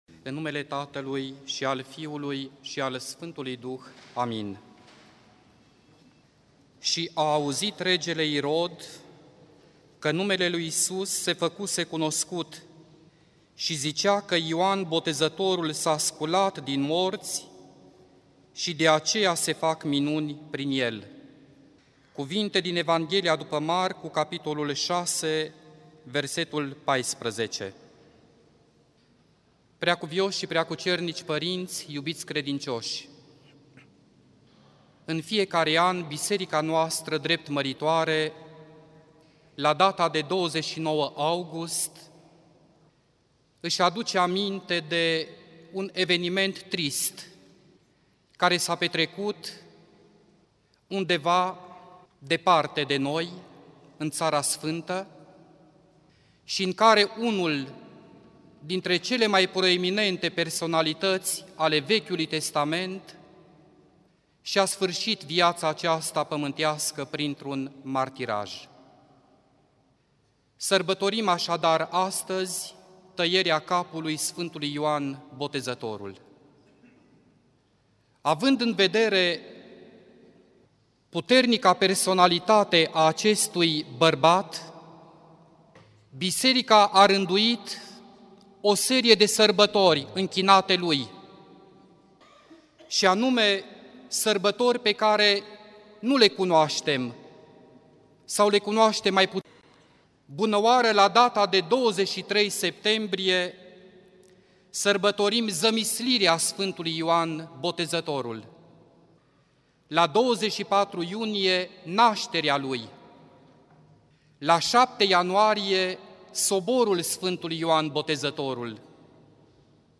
Predică la sărbătoarea Tăierii capului Sf.
Cuvinte de învățătură Predică la sărbătoarea Tăierii capului Sf.